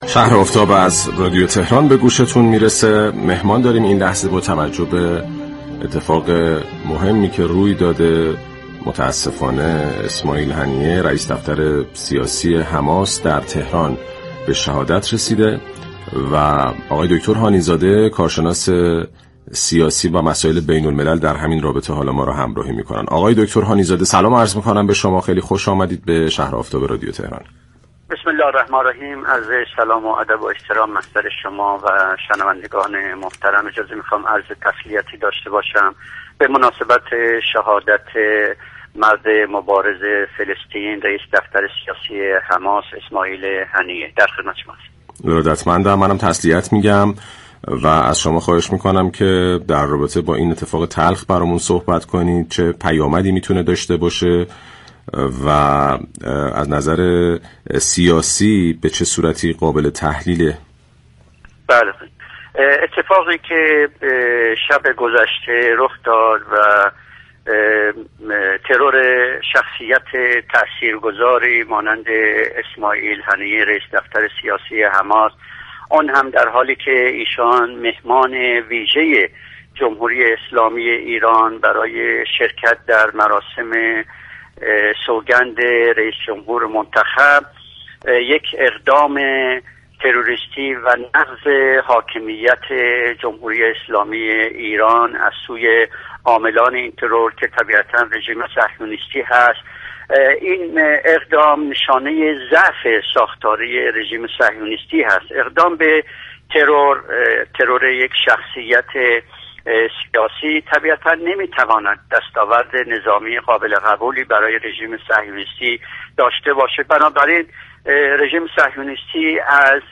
در گفت و گو با «شهر آفتاب»